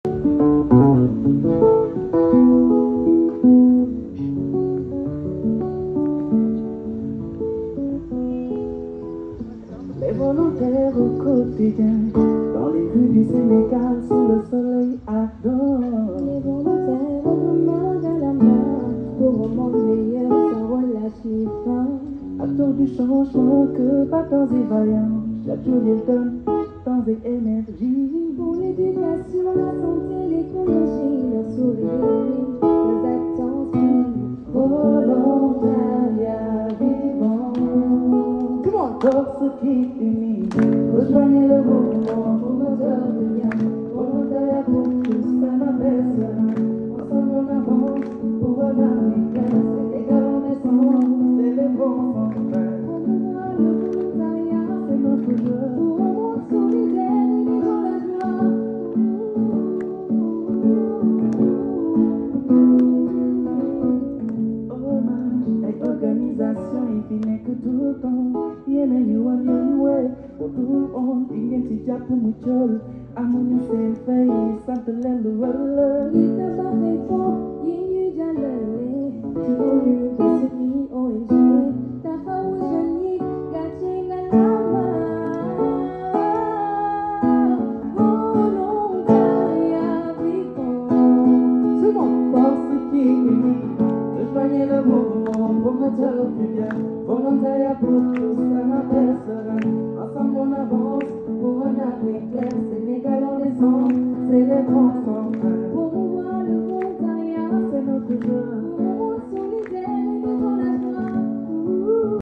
La cérémonie a été rythmée par différentes sonorités